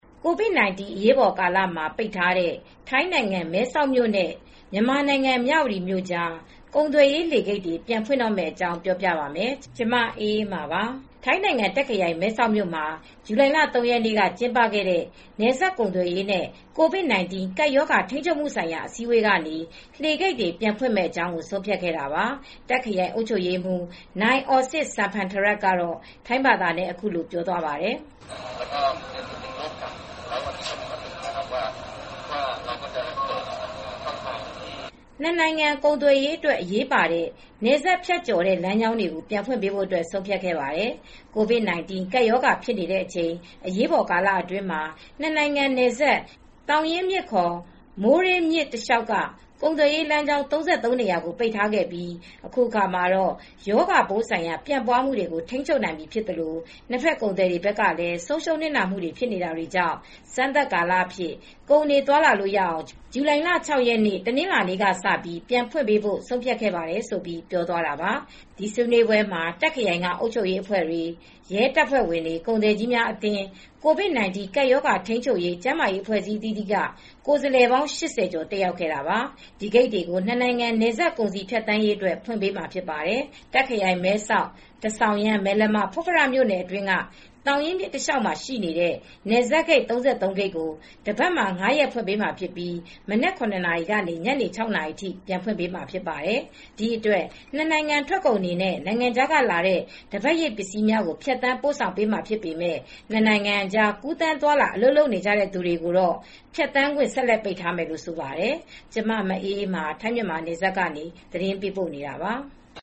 ထိုင်းနိုင်ငံ တာ့ခ်ခရိုင် မဲဆောက်မြို့မှာ ဇူလိုင်လ ၃ ရက်နေ့က ကျင်းပခဲ့တဲ့ နယ်စပ်ကုန်သွယ်ရေးနဲ့ COVID-19 ကပ်ရောဂါ ထိန်းချုပ်မှုဆိုင်ရာ အစည်းအဝေးကနေ လှေဂိတ်တွေ ပြန်ဖွင့်မယ့်အကြောင်း ဆုံးဖြတ်ခဲ့တာပါ။ တာ့ခ်ခရိုင် အုပ်ချုပ်ရေးမှူး Mr. Orsit Samphantharat က ထိုင်းဘာသာနဲ့ အခုလို ပြောသွားပါတယ်။